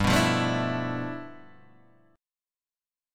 G 13th